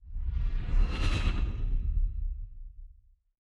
Distant Ship Pass By 7_5.wav